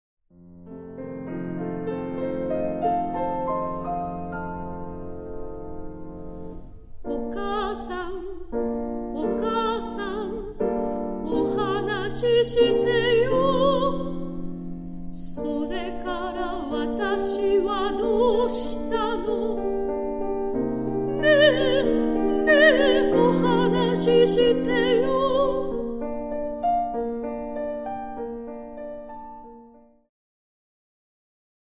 ジャンル POPS系
癒し系
歌・ピアノ
シタール